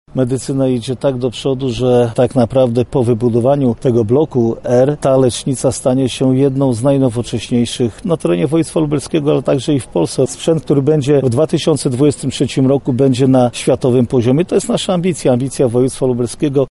• mówi Jarosław Stawiarski, marszałek województwa lubelskiego.